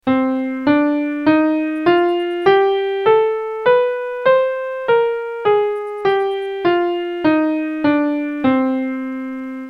CMinor.mp3